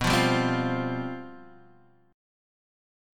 Csus/B chord